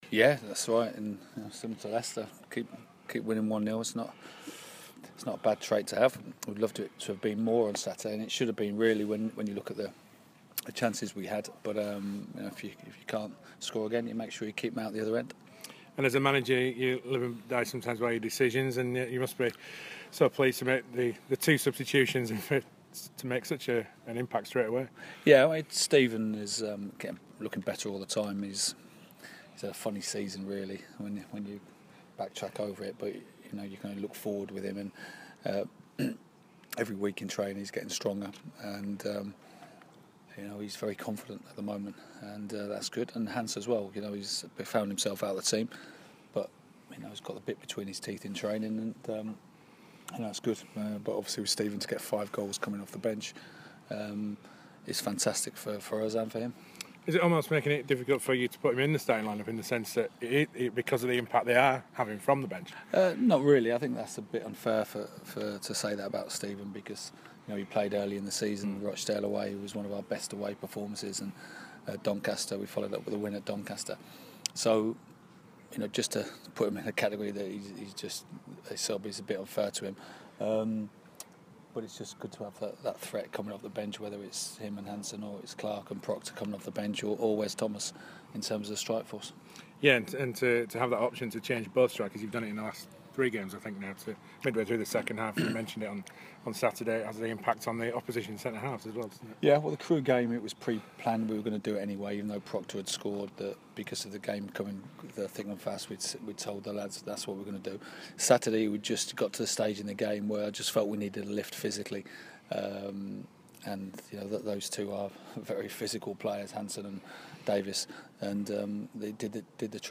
Bradford City manager Phil Parkinson talks to members of the media about the Swindon clash, the depth of the squad McArdle returning to contention and the aim he has for the end of the season.